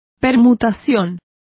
Complete with pronunciation of the translation of permutation.